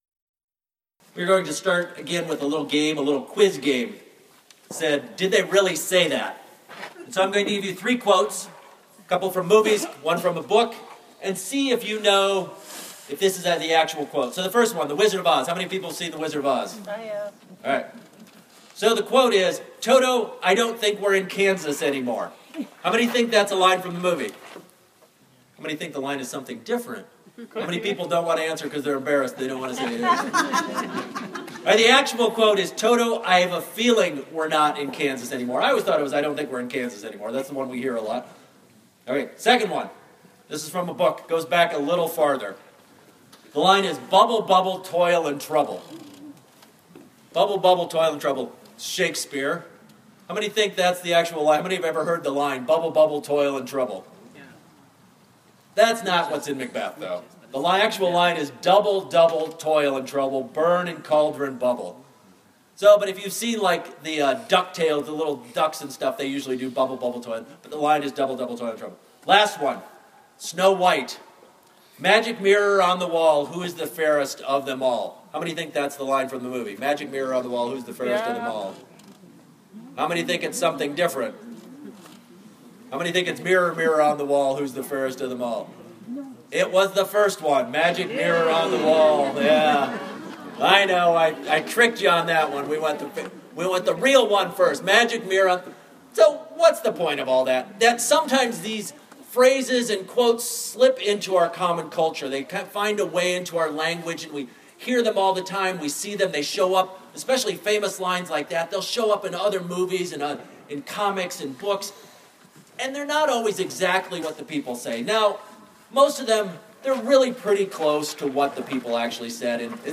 Sermons | Fruitland Covenant Church